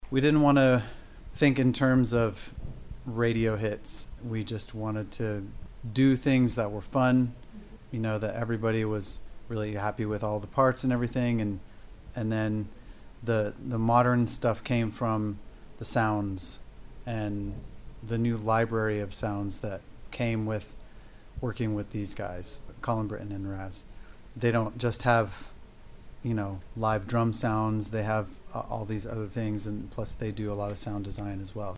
Gitarrist Jerry Horton erzählt im Interview, dass sie etwas machen wollten, mit dem jeder aus der Band zufrieden ist: